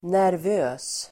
Uttal: [närv'ö:s]